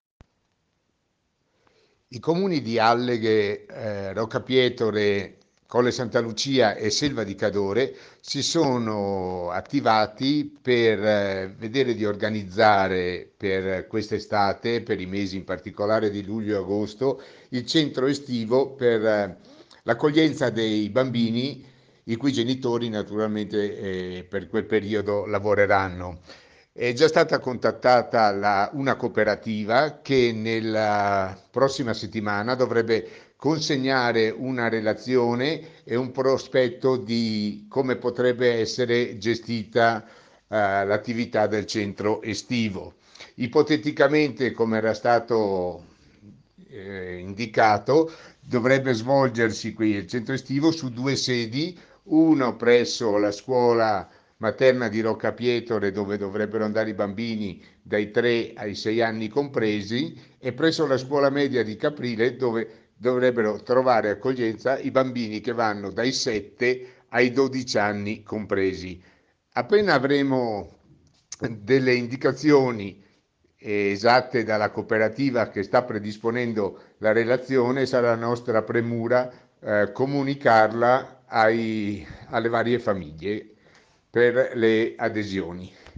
ALLEGHE In Val Cordevole ci si è già organizzati per un centro estivo. Ad annunciarlo il sindaco di Alleghe Danilo De Toni che spiega i particolari alla cittadinanza. Nell’iniziativa anche i comuni di Rocca Pietore, Selva di Cadore e Colle Santa Lucia